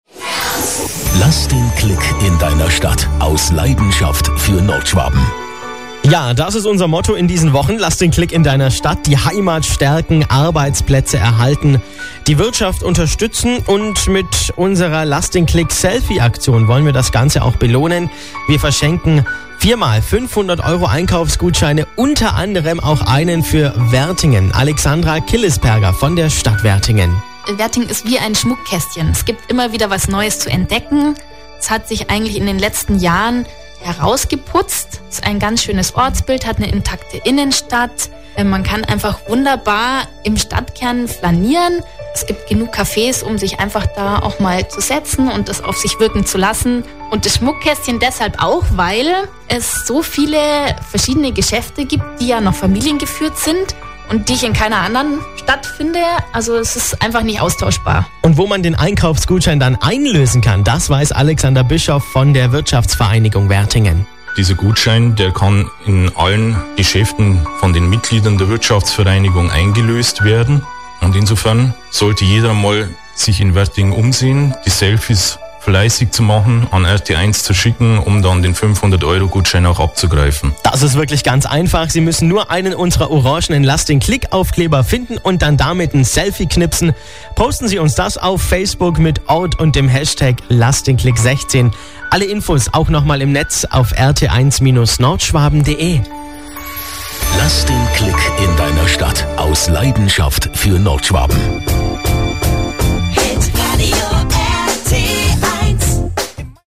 hitradio_rt1_nordschwaben_mitschnitt_ldk_wertingen.mp3